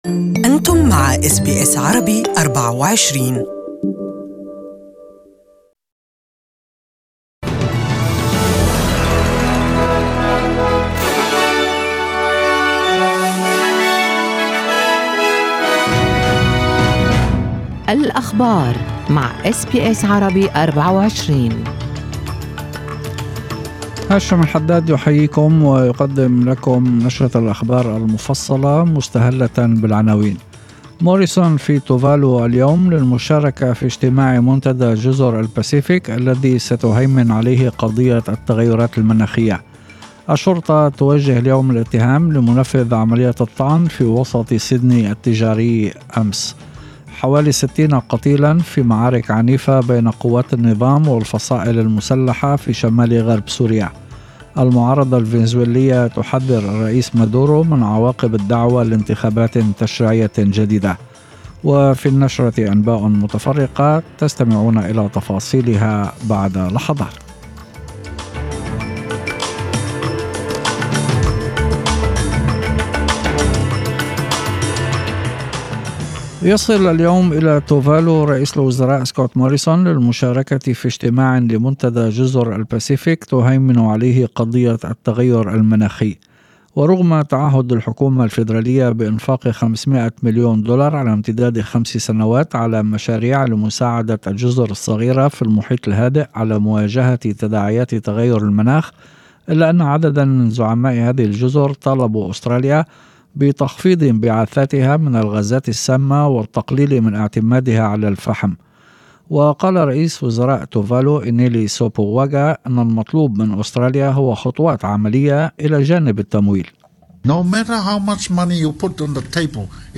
Evening Arabic News